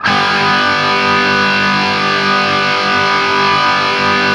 TRIAD G  L-R.wav